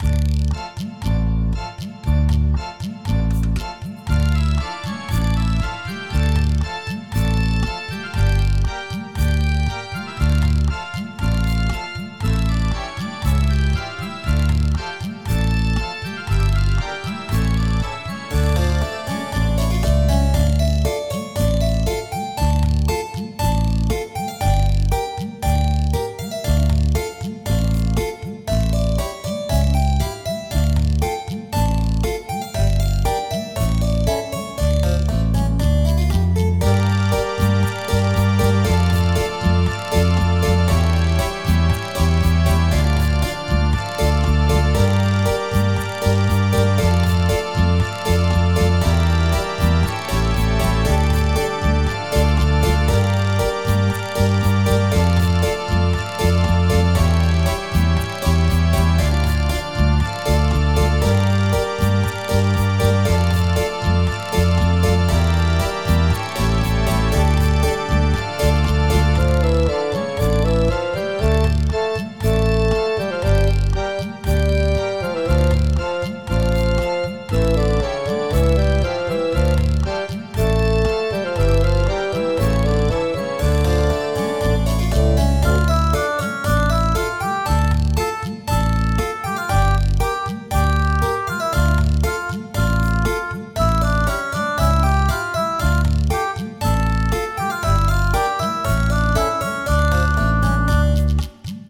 フリーBGM素材- あやしめな雰囲気の地下室とかそういう感じ。